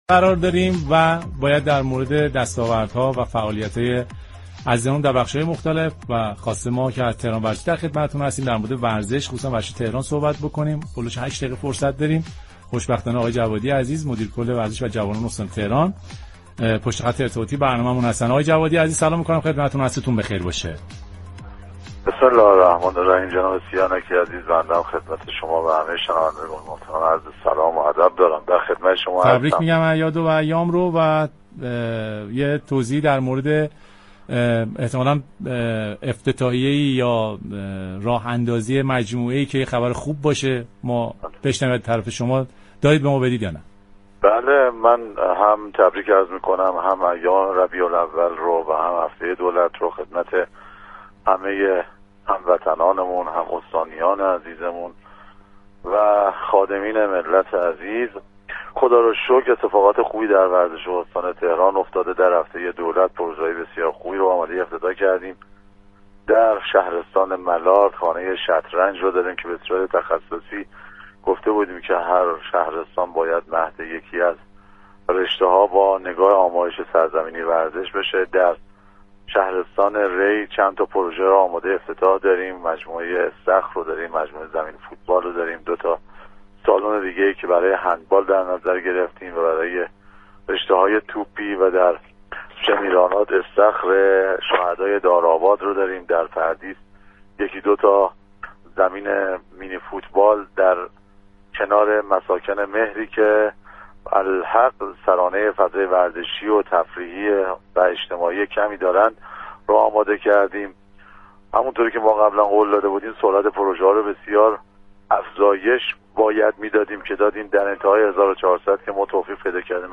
مدیركل ورزش و جوانان استان تهران در گفت‌وگو با رادیو تهران اعلام كرد كه با بازسازی و توسعه زیرساخت‌های ورزشی در شهرستان‌ها و تهران، پروژه‌های متعددی آماده افتتاح شده و تهران اكنون میزبان مسابقات لیگ برتر و لیگ یك فوتبال است. این اقدامات با هدف افزایش سرانه ورزشی و حمایت از استعدادهای جوان در هفتاد و شش رشته ورزشی انجام شده است.